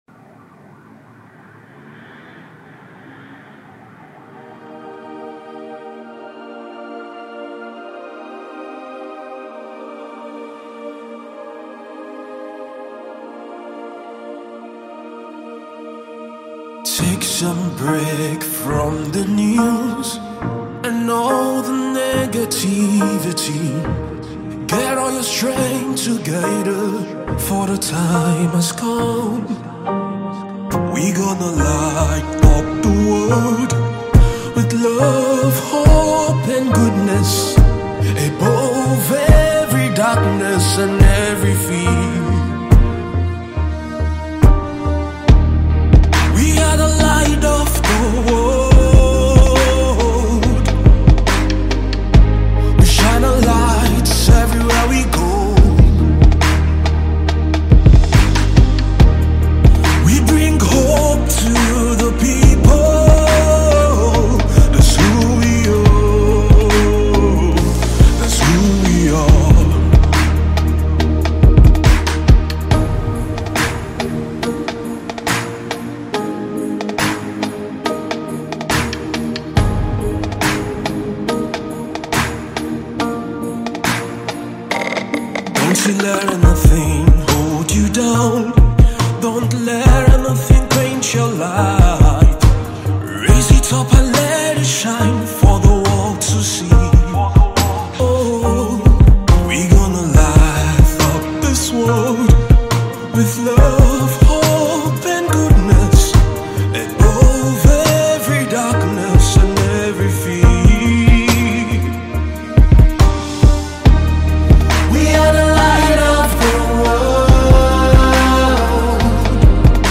spirit-lifting track